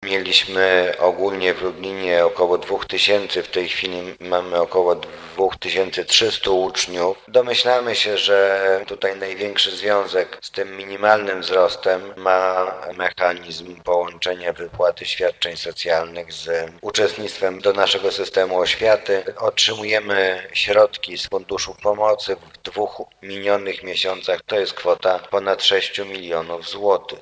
Wzrosła liczba uczniów z Ukrainy w szkołach w Lublinie. Poinformował o tym – podczas sesji Rady Miasta – zastępca prezydenta Lublina ds. oświaty i wychowania, Mariusz Banach.